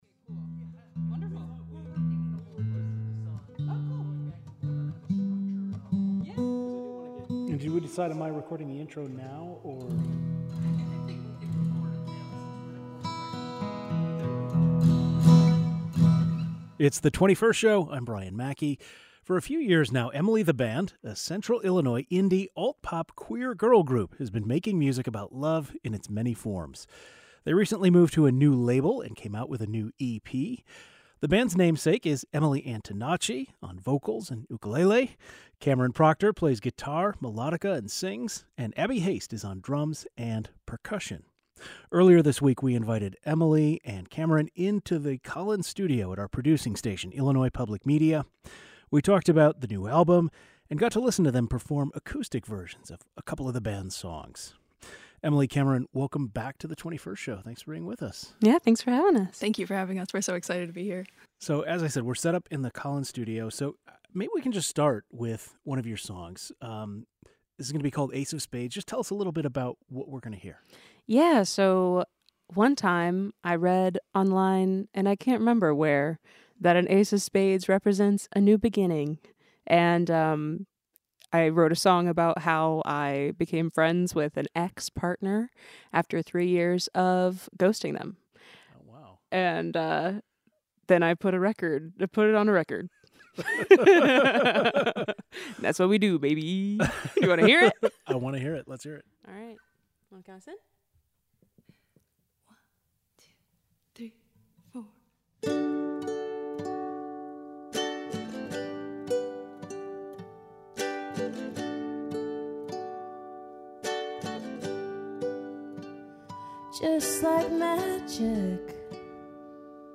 In March 2025, we talked about about the new album, and listened to them perform acoustic versions of a couple of the band's songs.